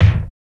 • 00s Rich Mid-Range Kick Drum Sample G Key 104.wav
Royality free kick single hit tuned to the G note. Loudest frequency: 275Hz
00s-rich-mid-range-kick-drum-sample-g-key-104-Ext.wav